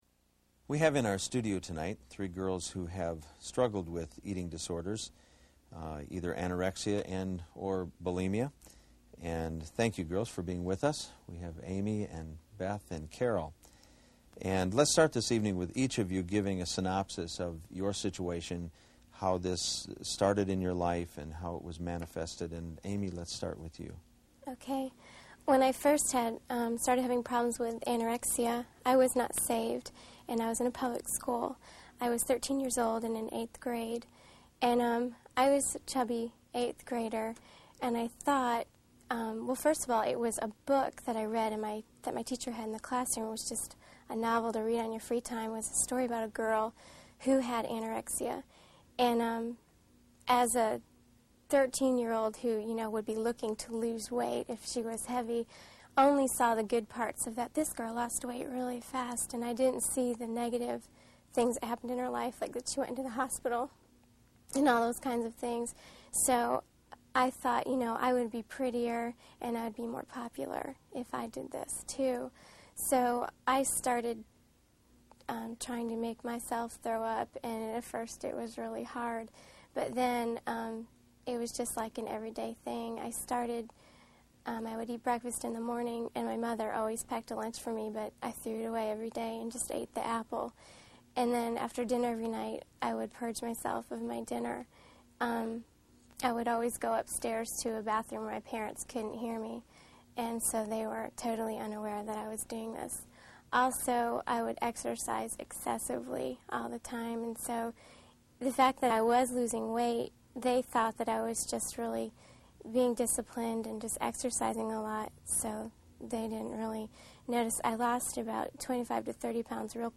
Biblically Overcoming Anorexia and Bulimia Audio Lecture (MP3)